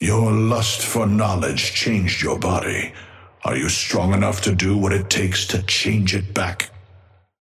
Amber Hand voice line - Your lust for knowledge changed your body.
Patron_male_ally_dynamo_start_04.mp3